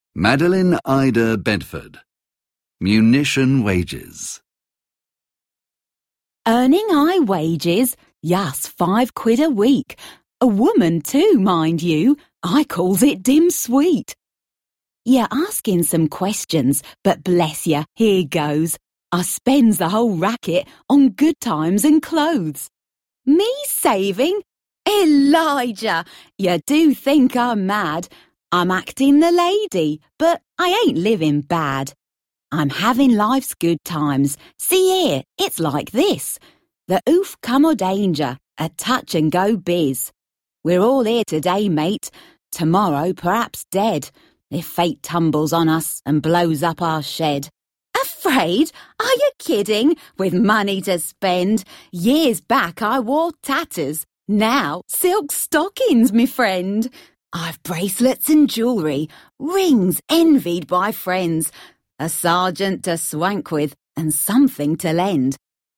Audiokniha A collection Of First World War Poetry namluvená rodilým mluvčím.